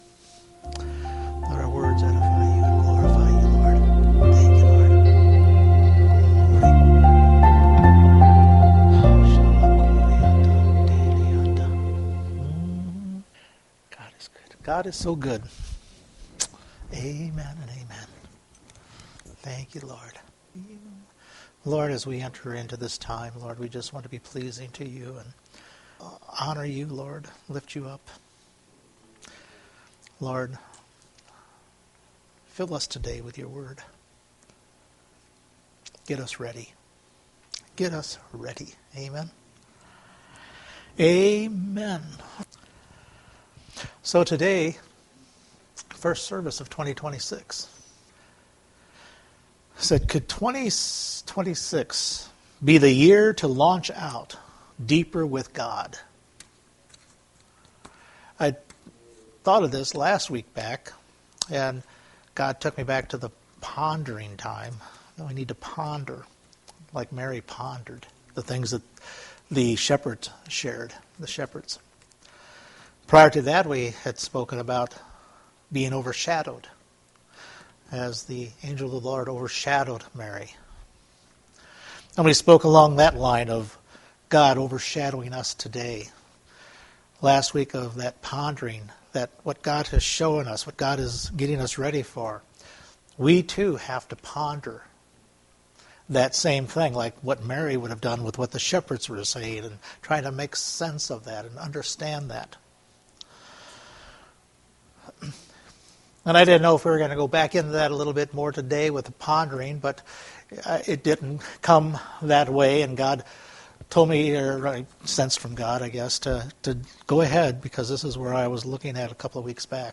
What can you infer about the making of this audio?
4 Service Type: Sunday Morning Listen closely to God.